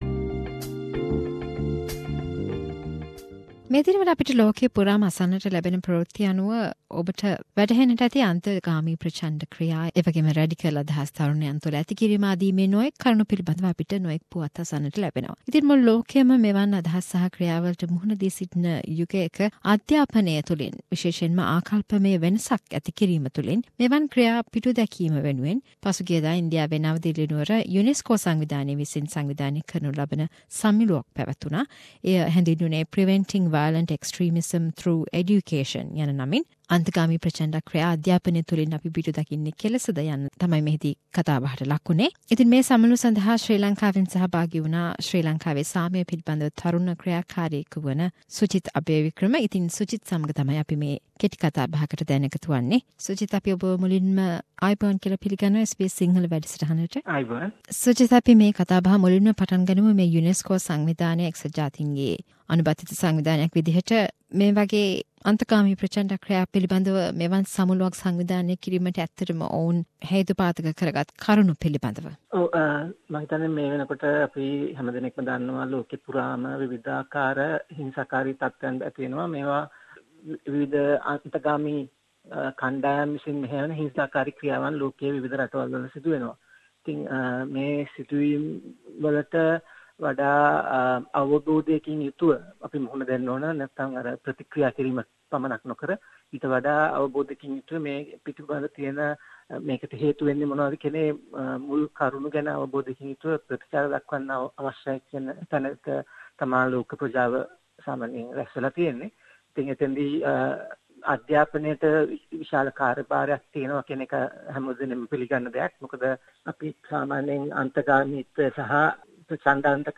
chat with a Sri Lankan who participated the Unesco extremism summit